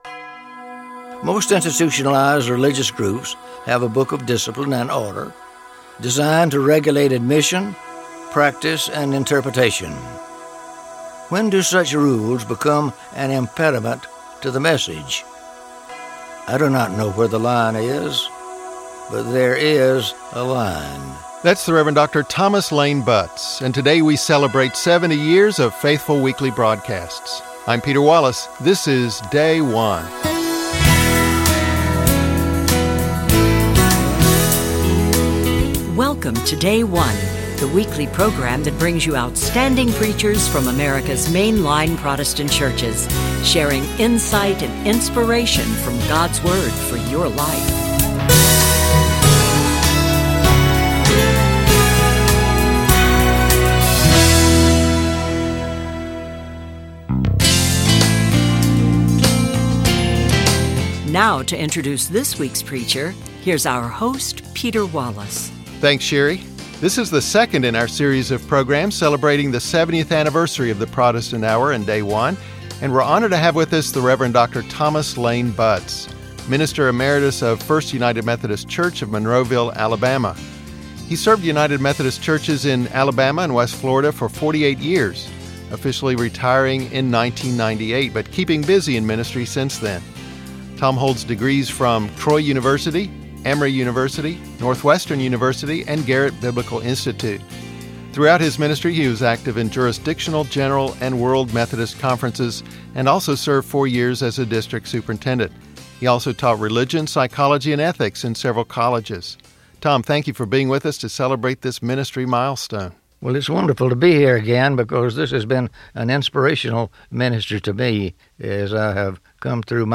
United Methodist Church 6th Sunday after Pentecost - Year B Mark 6:1-6